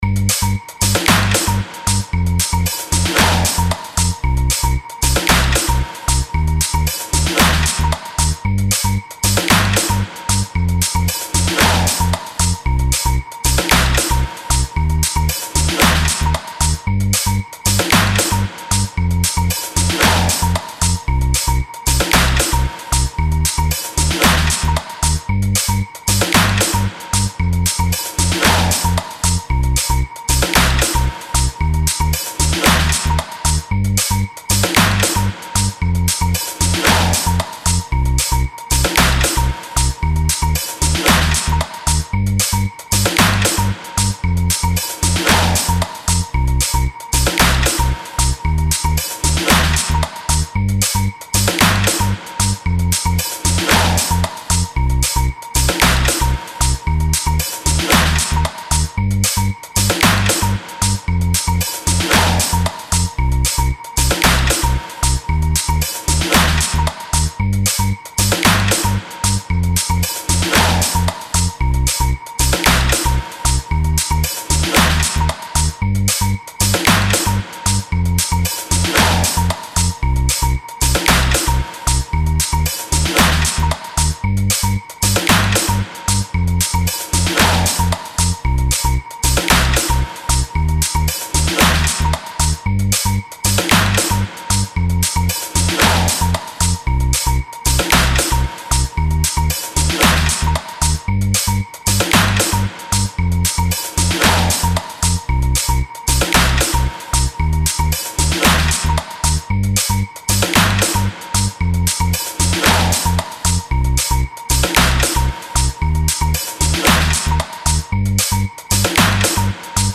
Home > Music > Beats > Bright > Running > Chasing